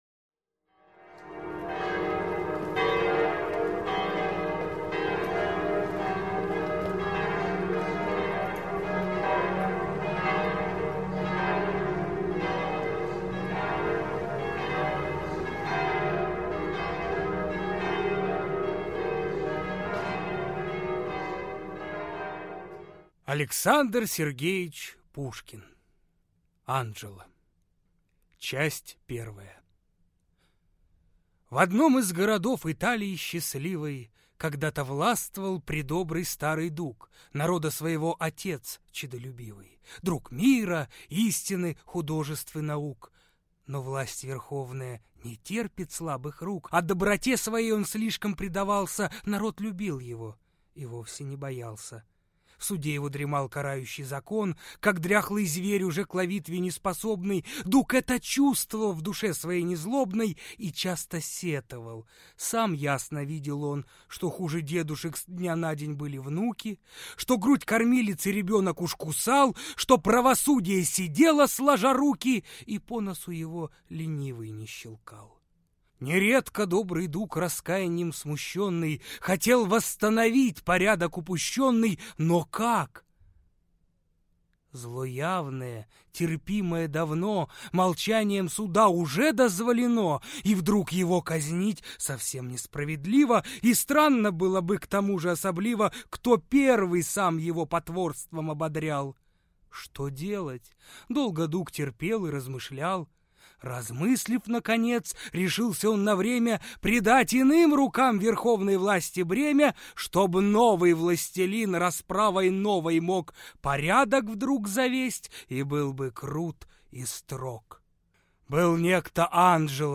Аудиокнига Анджело | Библиотека аудиокниг